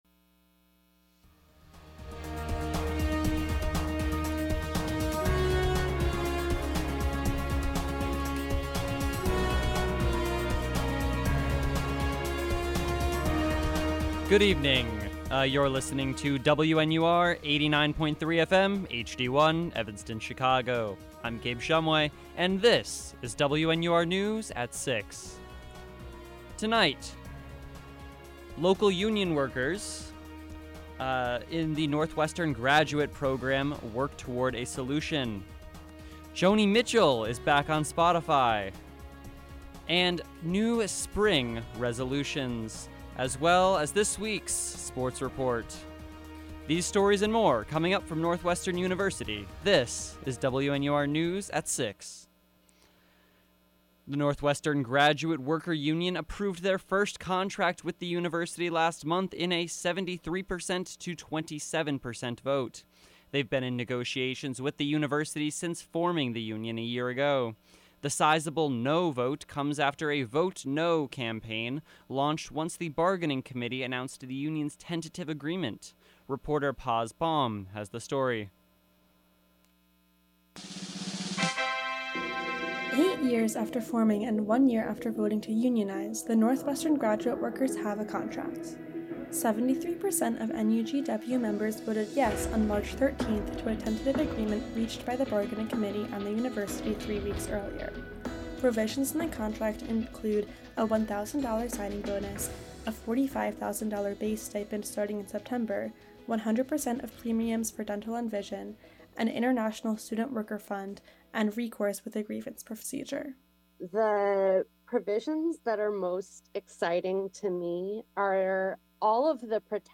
April 3rd, 2024: NUGW,Joni Mitchell,spring break habits, and the Northwestern Sports Report. WNUR News broadcasts live at 6 pm CST on Mondays, Wednesdays, and Fridays on WNUR 89.3 FM.